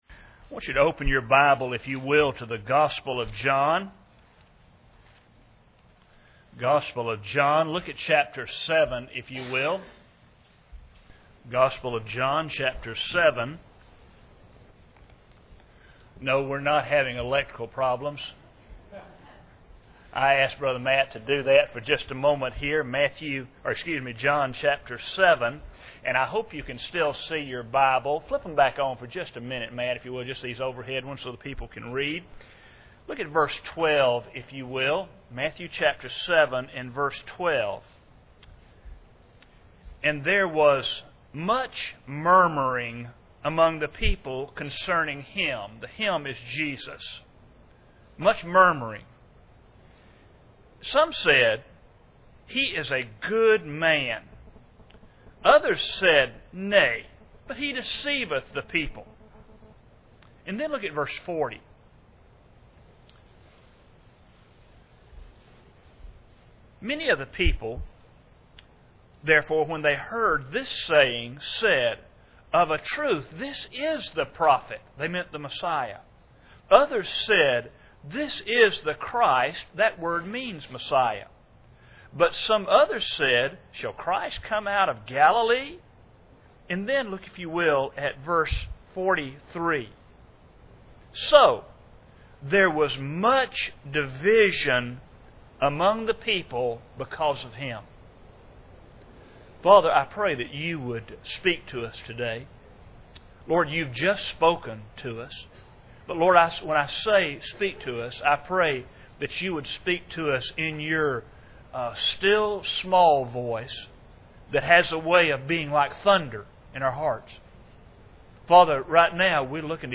He is unable to finish the sermon due to the many testimonies offered by the people.
Service Type: Sunday Morning